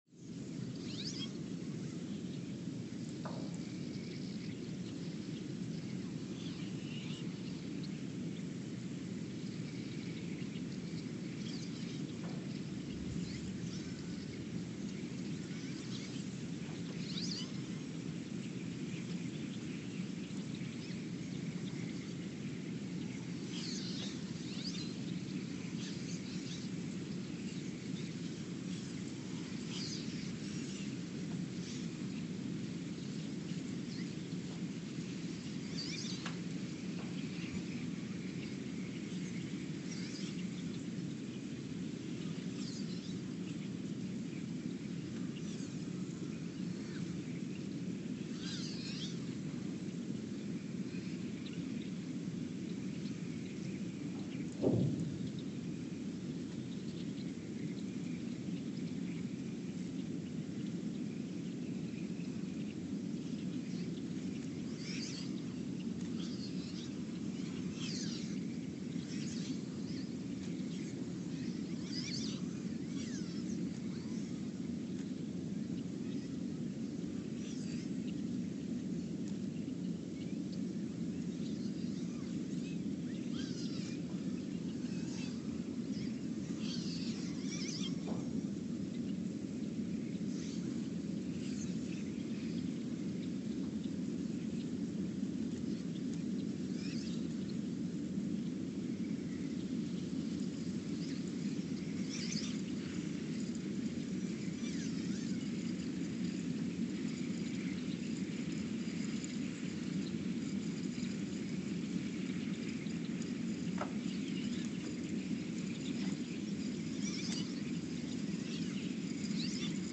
The Earthsound Project: Ulaanbaatar, Mongolia (seismic) archived on November 14, 2020
The Earthsound Project is an ongoing audio and conceptual experiment to bring the deep seismic and atmospheric sounds of the planet into conscious awareness.
Sensor : STS-1V/VBB Recorder : Quanterra QX80 @ 20 Hz
Speedup : ×900 (transposed up about 10 octaves)
Loop duration (audio) : 11:12 (stereo)